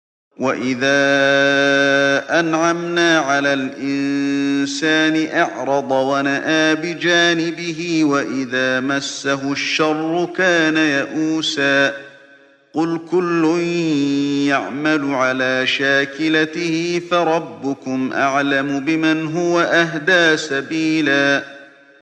Чтение аятов 83-84 суры «аль-Исра» шейхом ’Али бин ’Абд ар-Рахманом аль-Хузейфи, да хранит его Аллах.